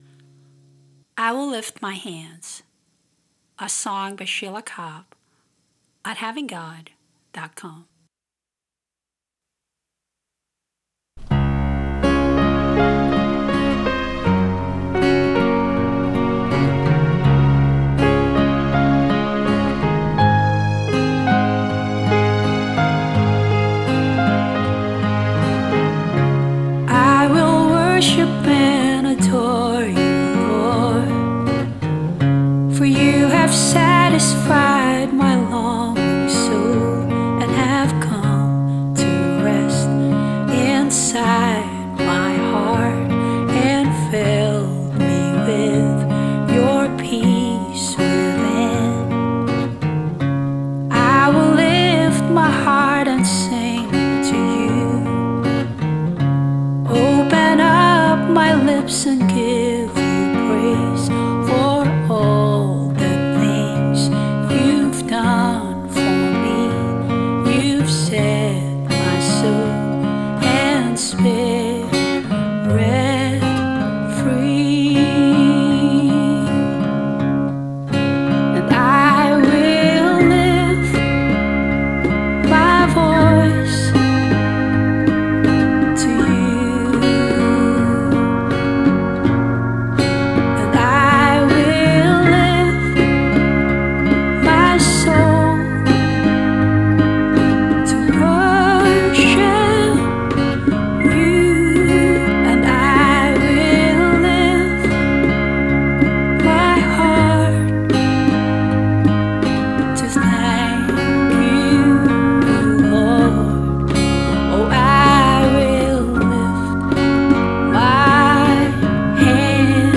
One of my favorite songs I sing to the Lord.